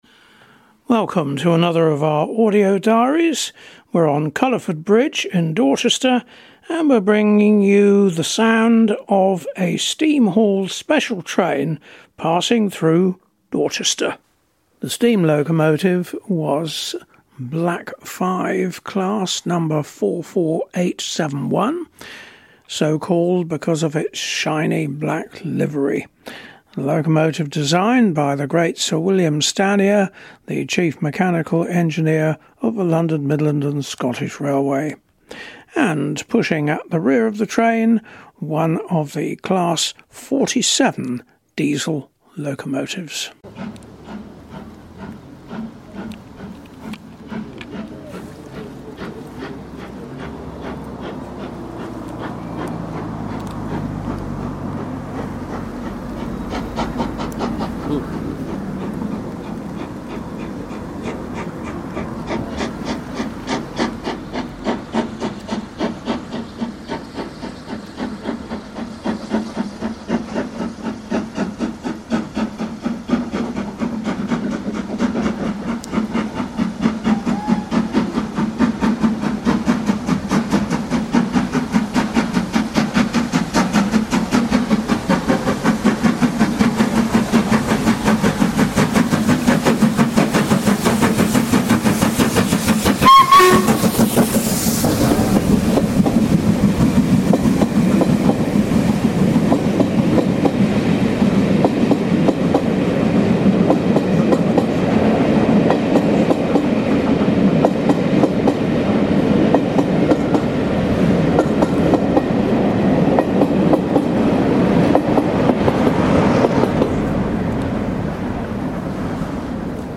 The sound of steam chuffing through Dorchester